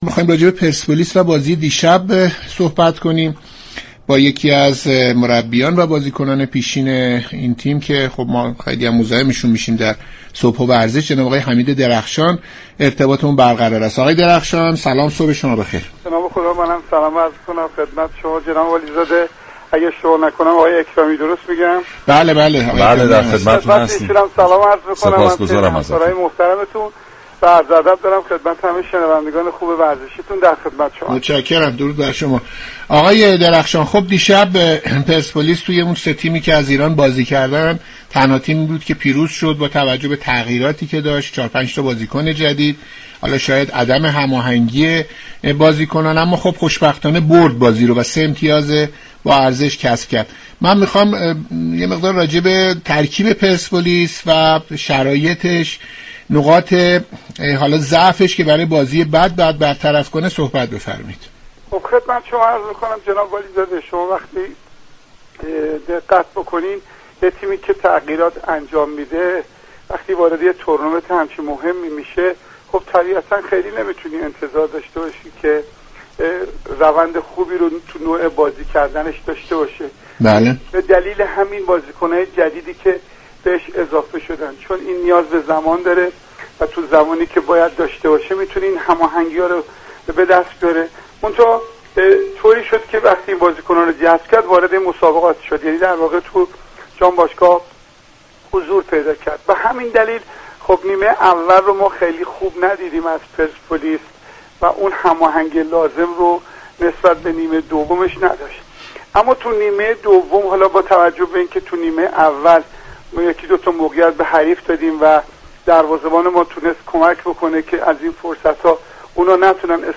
برنامه «صبح و ورزش» چهارشنبه 26 شهریور در گفتگو با حمید درخشان، مربی و بازیكن پیشین پرسپولیس به نقد بازی دیشب پرسپولیس با التعاون عربستان پرداخت.